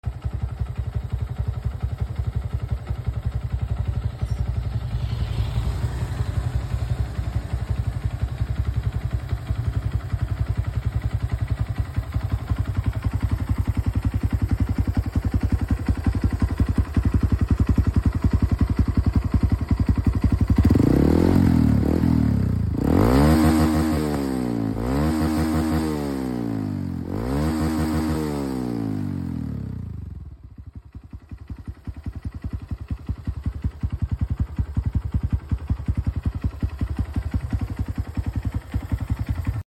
Cek sound kenalpot Husqivarna X sound effects free download